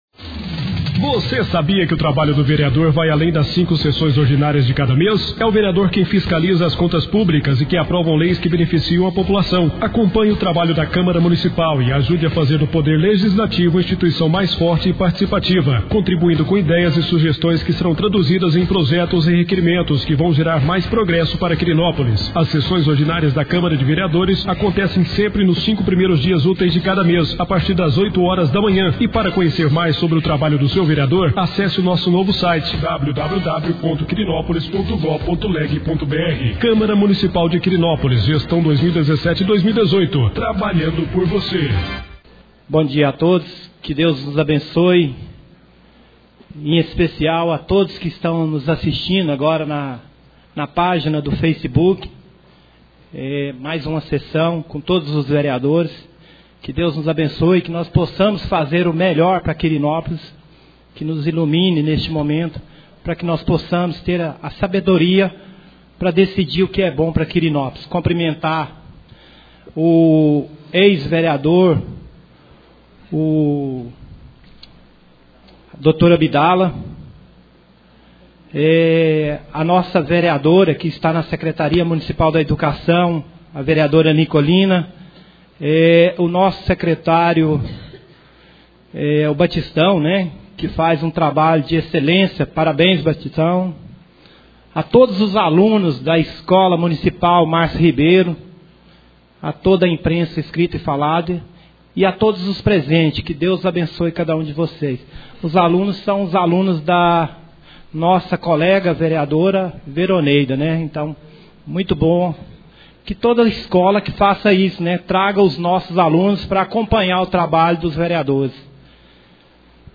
2° Sessão Ordinária do Mês de Junho 2017.